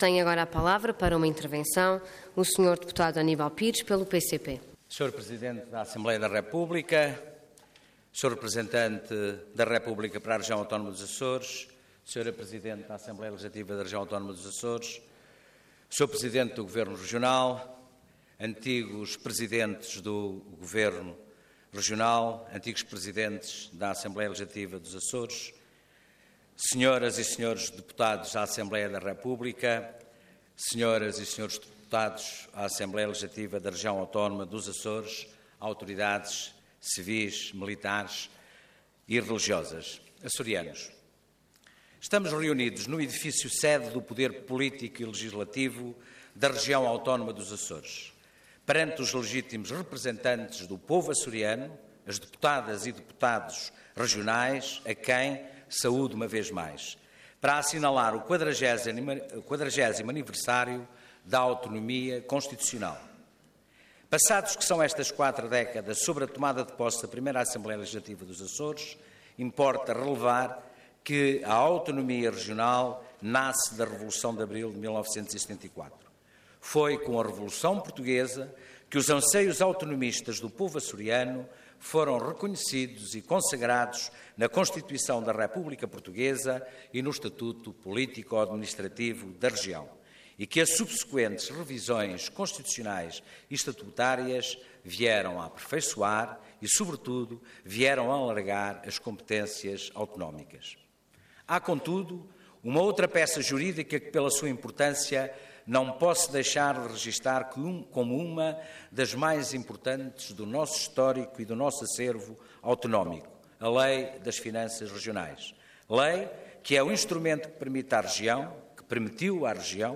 Parlamento online - Sessão Solene Evocativa dos 40 anos da Autonomia dos Açores
Detalhe de vídeo 4 de setembro de 2016 Download áudio Download vídeo X Legislatura Sessão Solene Evocativa dos 40 anos da Autonomia dos Açores Intervenção Orador Aníbal Pires Cargo Deputado Entidade PCP